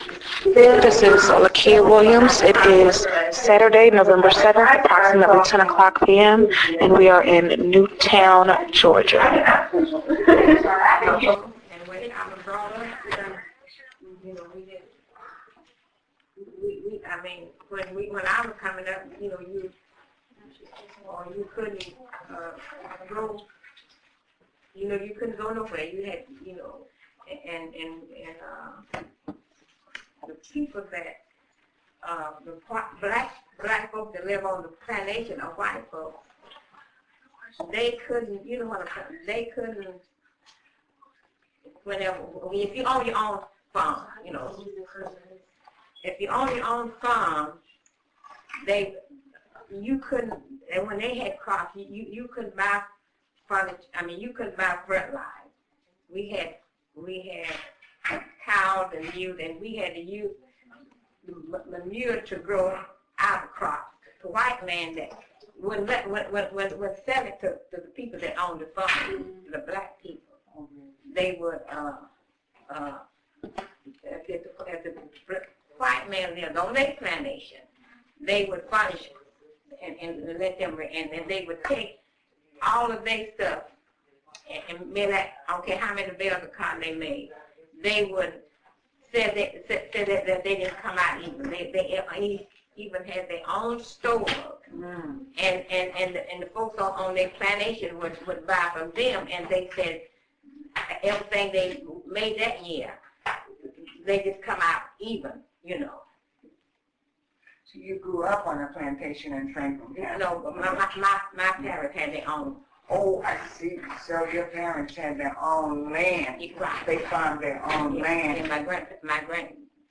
Oral histories of women from Newtown, an African-American neighborhood in Gainesville, Georgia, whose Florist Club members became vocal leaders for civil rights and community improvement.